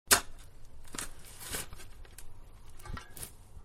Shovel5.wav